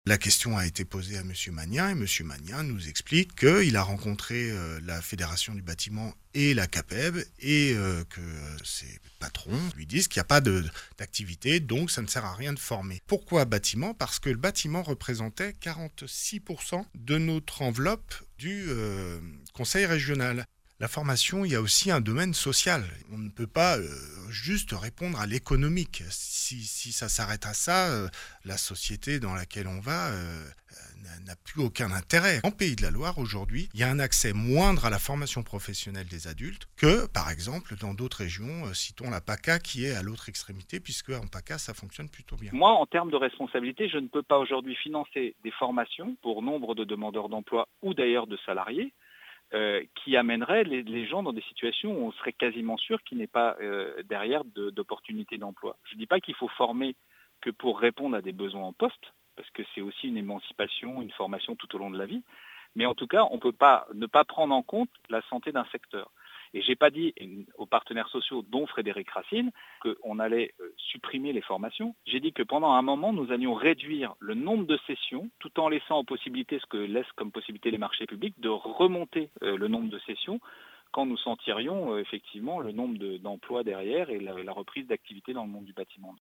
suivit du droit de réponse de Jean-Philippe Magnen sur SUN.